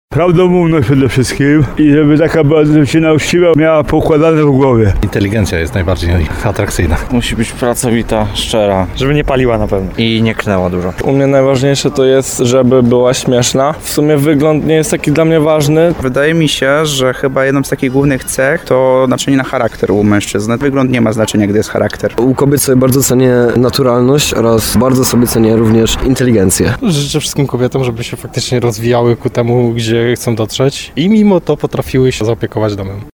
My postanowiliśmy zapytać mieszkańców Tarnowa, jakie cechy cenią u kobiet najbardziej.
sonda